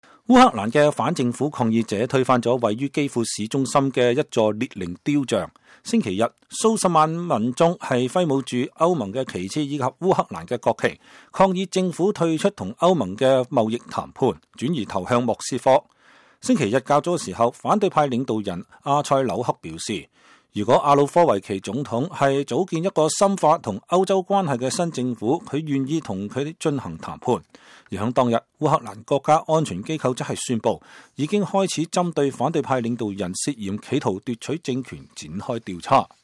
烏克蘭的反政府抗議者在街頭唱過﹐堅持留在街頭﹐繼續鬥爭。